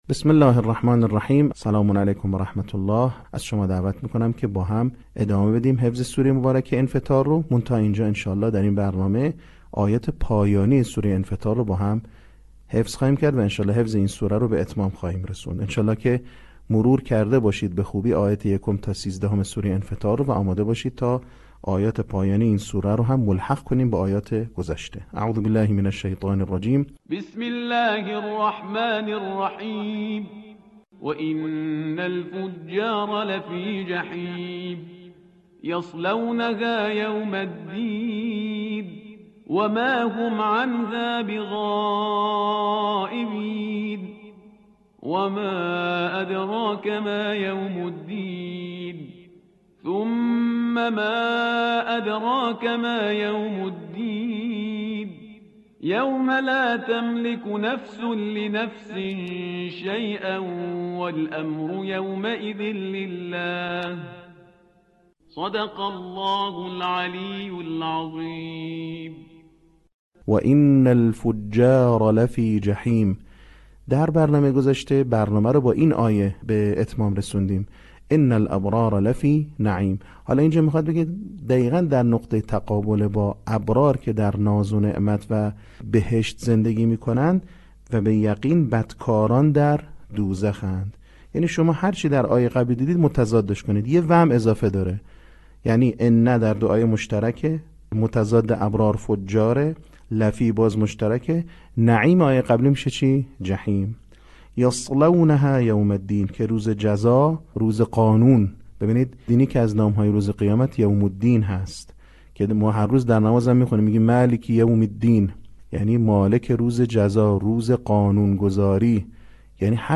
صوت | بخش سوم آموزش حفظ سوره انفطار